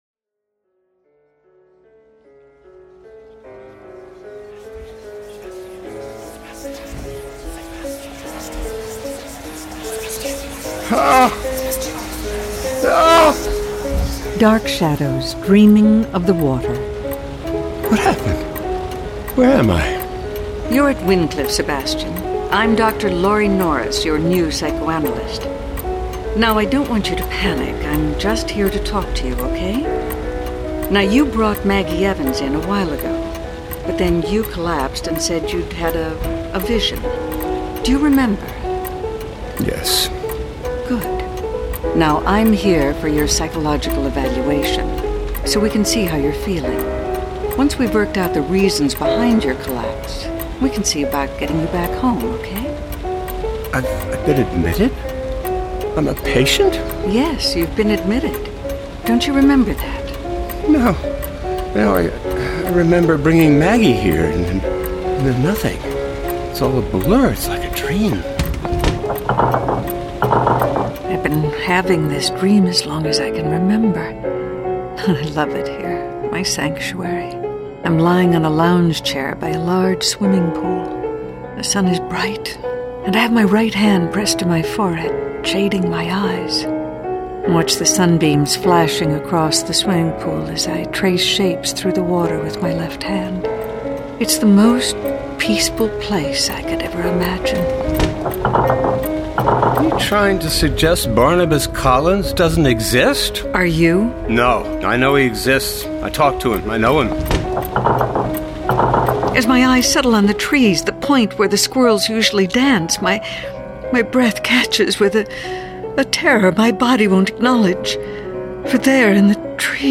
Dark Shadows - Dramatised Readings 30. Dark Shadows: Dreaming of the Water